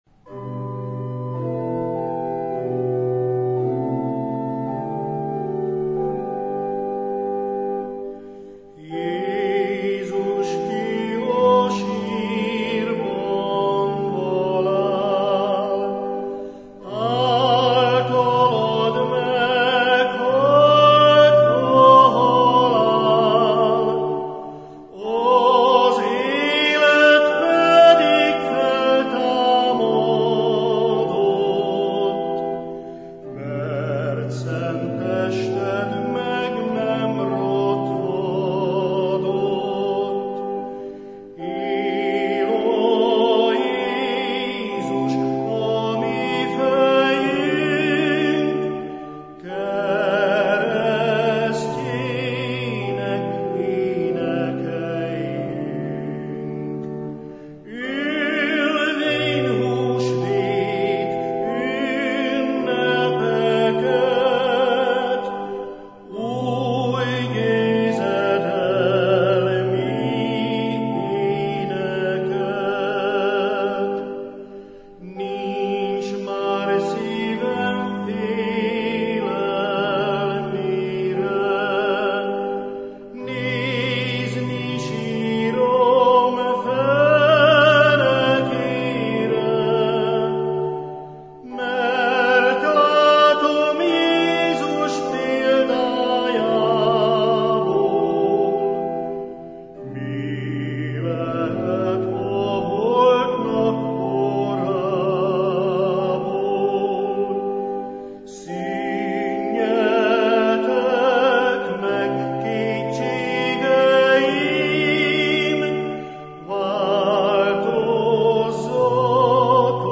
Református felekezet 2017. április 2-i adása – Bocskai Rádió